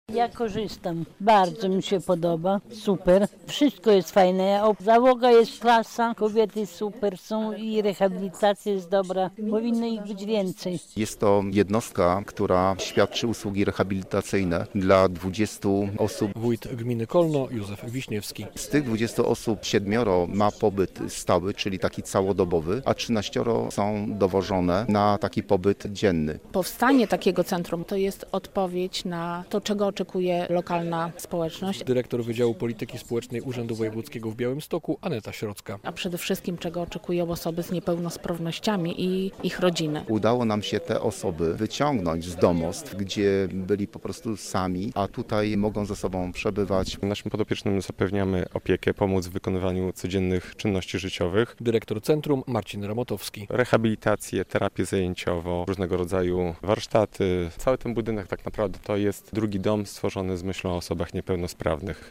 W Centrum Opiekuńczo-Mieszkalnym opiekę otrzymuje stale 20 osób - mówi wójt gminy Kolno Józef Wiśniewski.